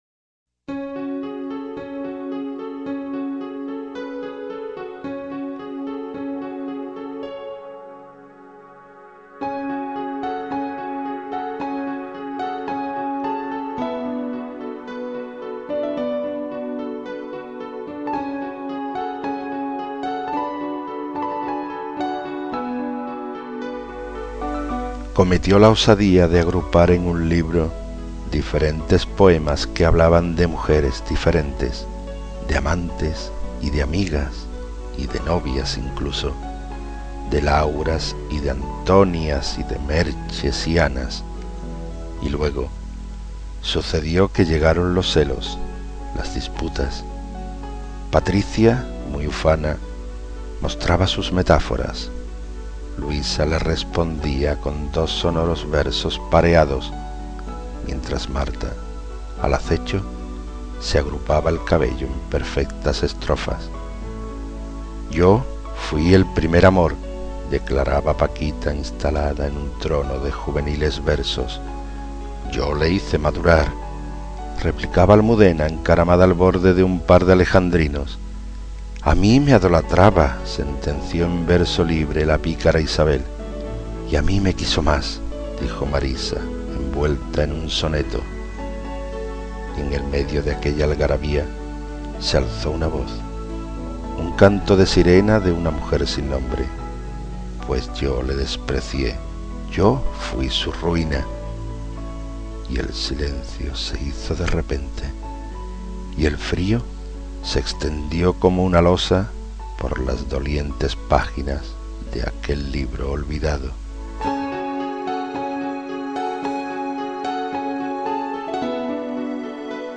Inicio Multimedia Audiopoemas Escena de celos.